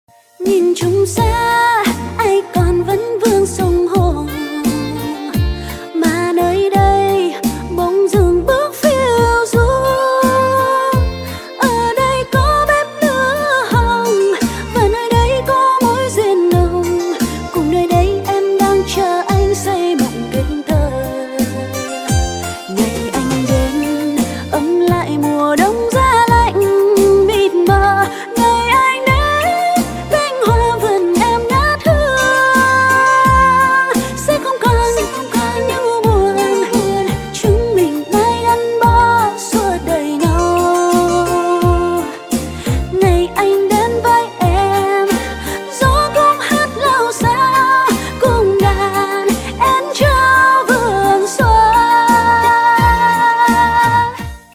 Nhạc Chuông Trữ Tình